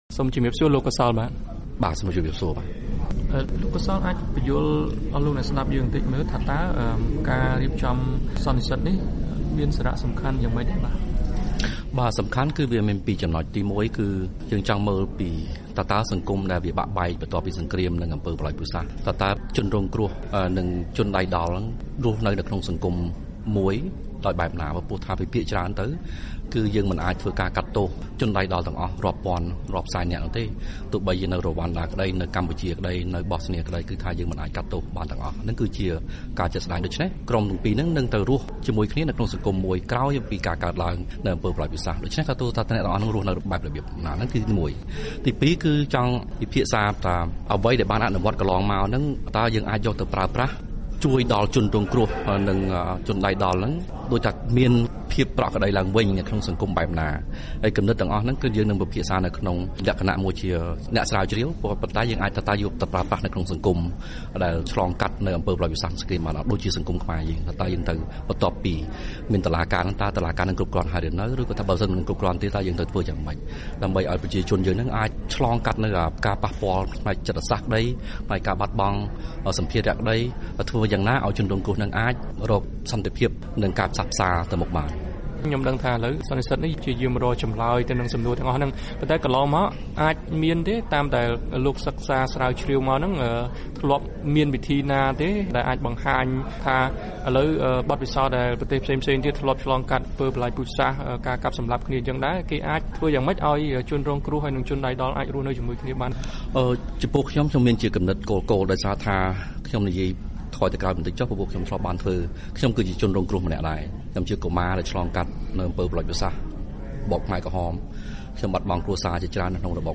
បទសម្ភាសន៍ VOA៖ ការផ្សះផ្សារវាងអតីតកម្មាភិបាលខ្មែរក្រហម និងជនរងគ្រោះនៃរបបខ្មែរក្រហម